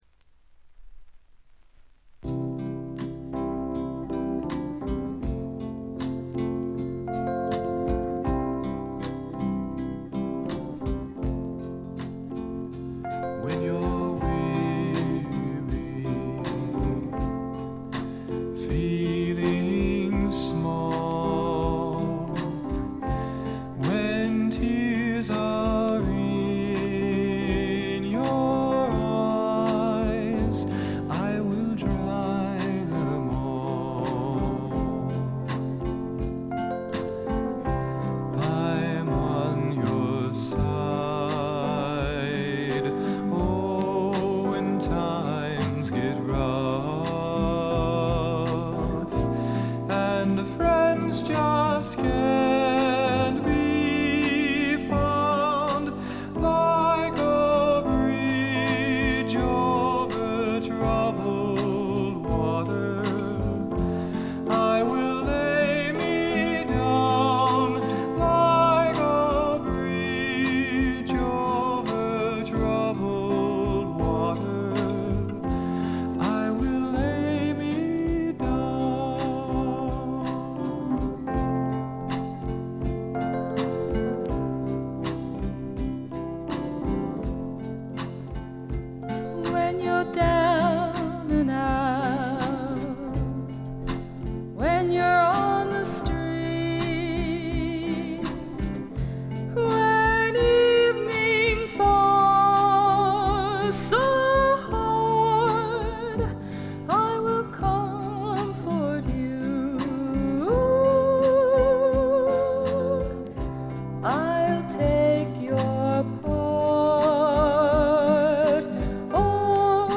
プレスのためかバックグラウンドノイズ、チリプチが出ます。
知る人ぞ知る自主制作ポップ・フォークの快作です。
試聴曲は現品からの取り込み音源です。